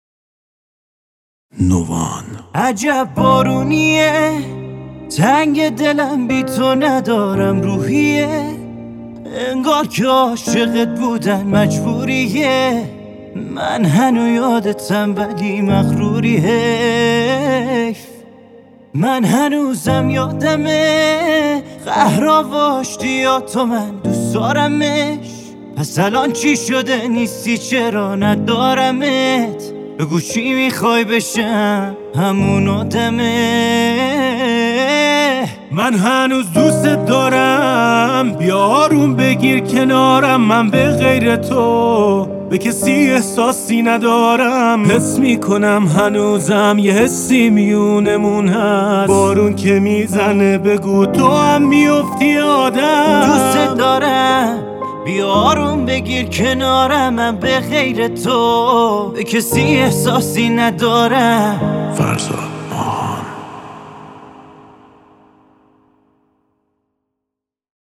با گیتار